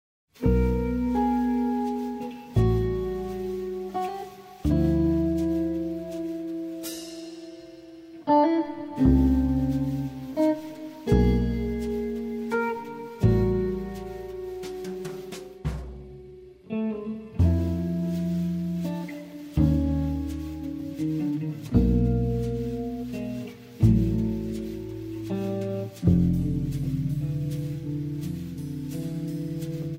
Guitar and Processed Guitar
Acoustic Bass
Drums and Percussion